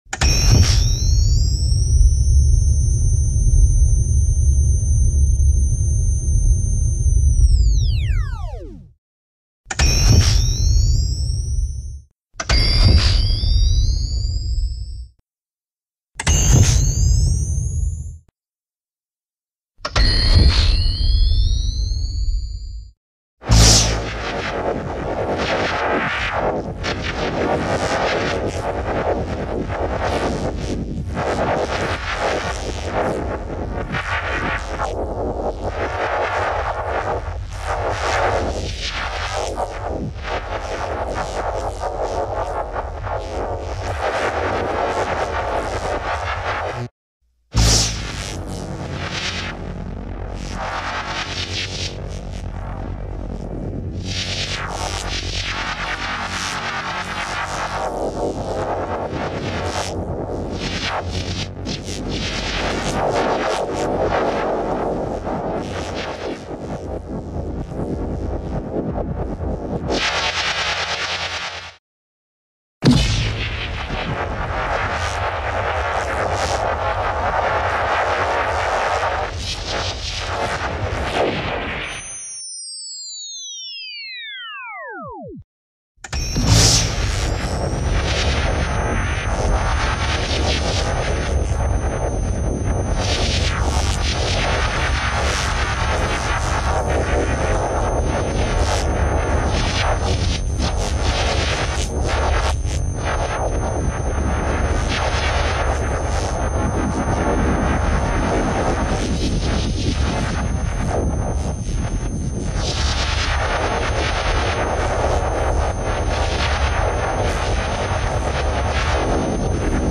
Звуковая серия оружия охотников за привидениями протон